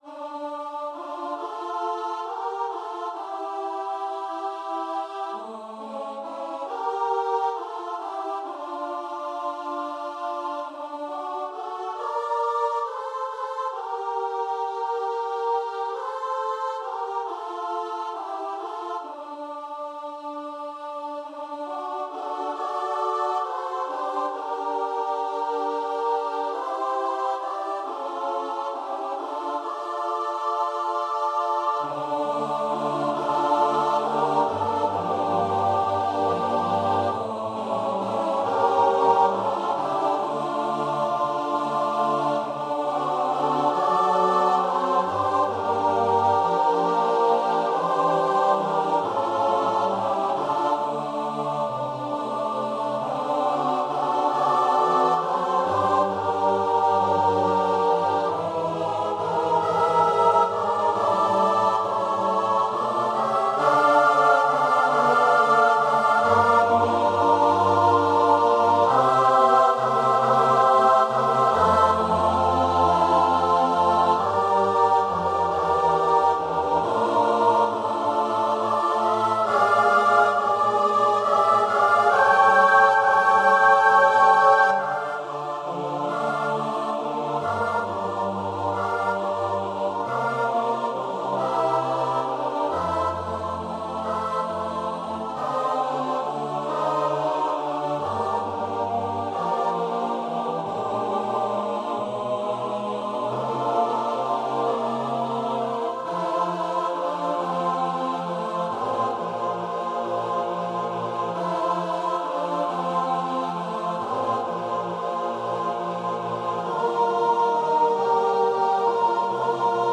Ноты, хор, партитура голосов.
*.mid - МИДИ-файл для прослушивания нот.